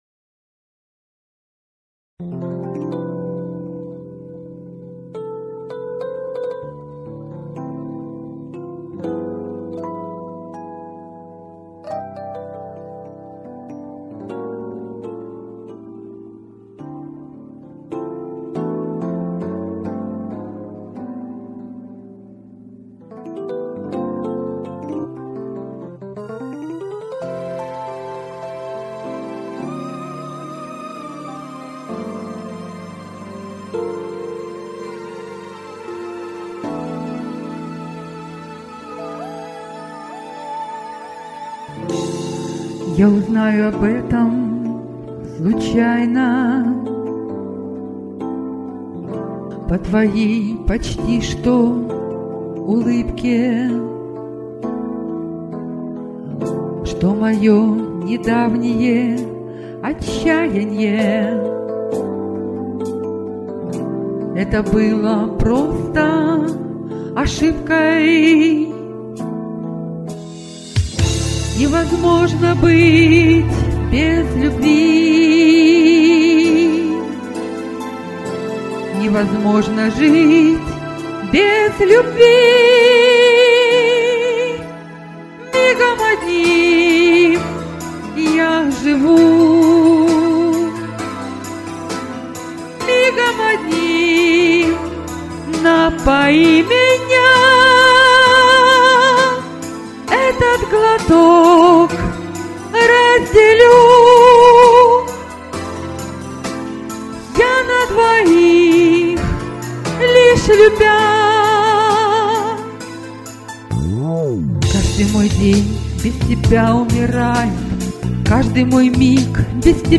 Текст,  исполнение и мотив песни - мои.